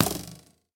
Стрела воткнулась в цель
bowhit2.mp3